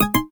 volumedown.ogg